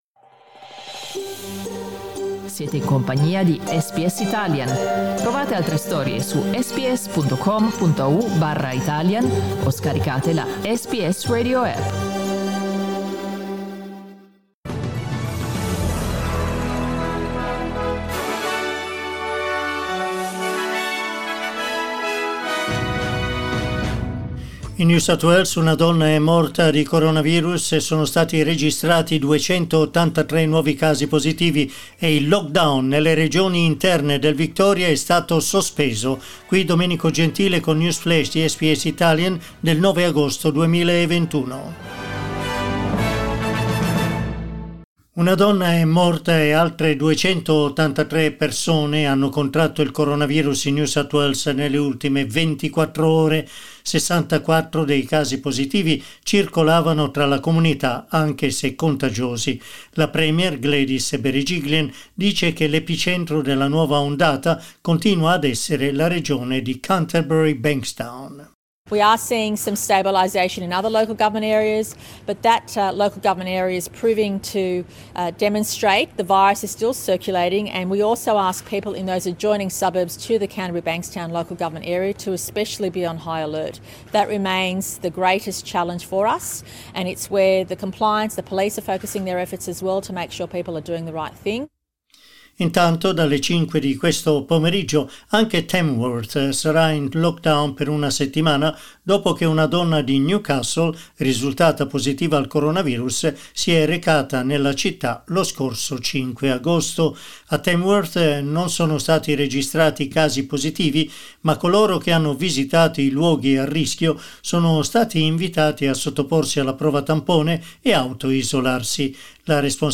News Flash di lunedì 9 agosto 2021
L'aggiornamento delle notizie di SBS Italian.